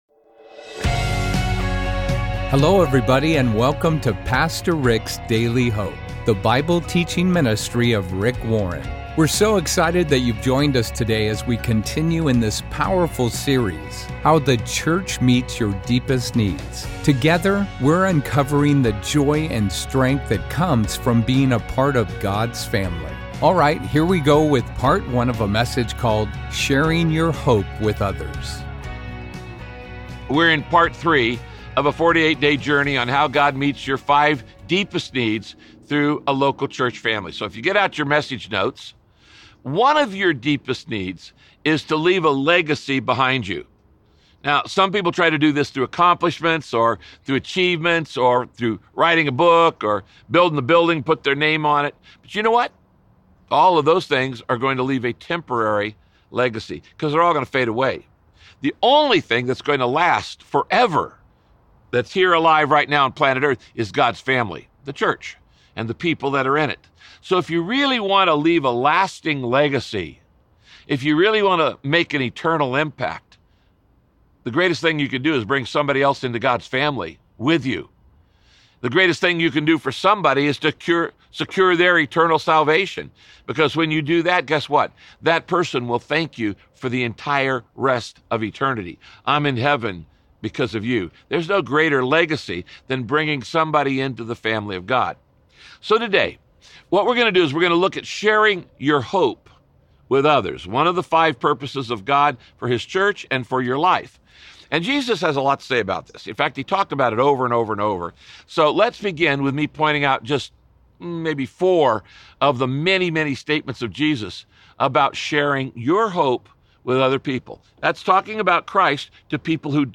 When you tell others about the meaning and significance they will find in Jesus, you're offering them exactly what they're looking for. In this broadcast, Pastor Rick teaches principles that will give you confidence to share your faith.